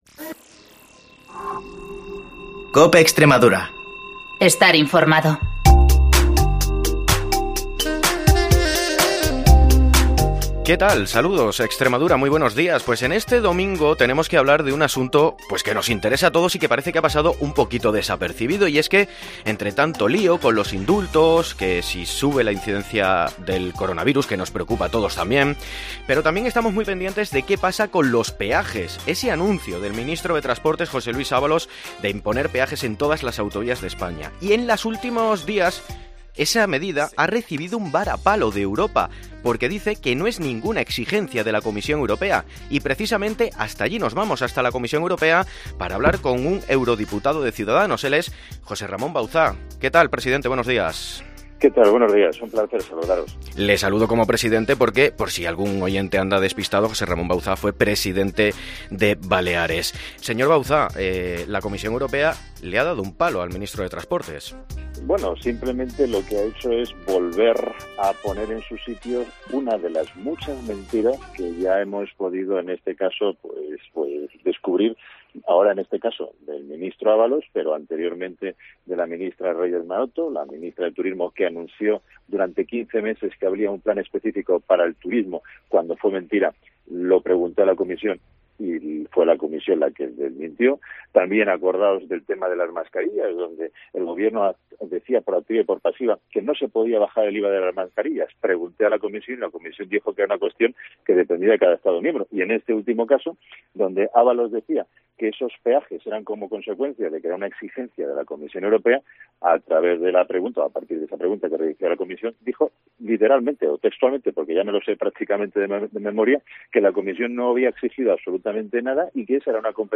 Entrevista
Entrevista a José Ramón Bauzá, diputado de Ciudadanos en el Parlamento Europeo